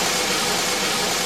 normal-sliderslide.wav